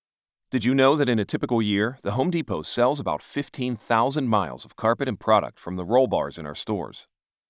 Professional American male voice. Conversational, guy next door, instructional, strong and occasionally snarky!
spot from a Home Depot learning module
Middle Aged